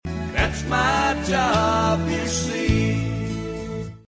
Category Country